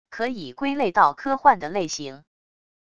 可以归类到科幻的类型wav音频